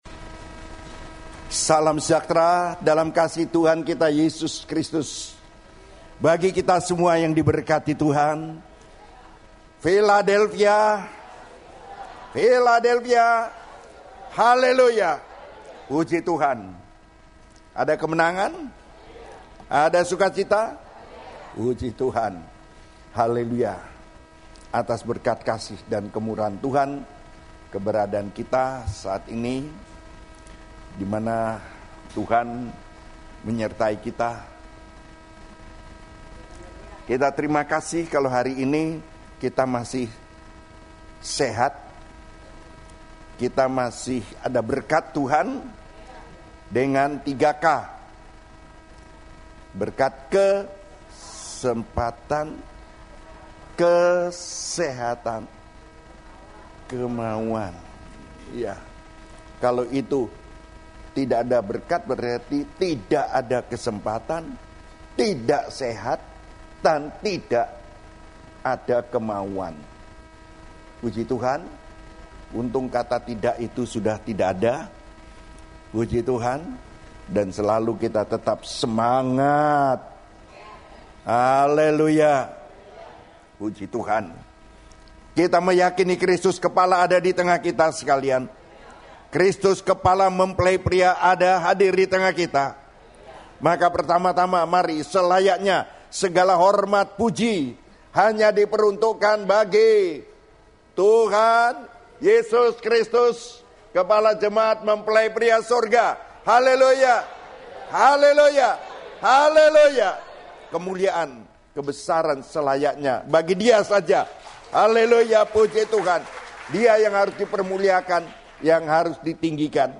Khotbah (Audio)
Khotbah Pengajaran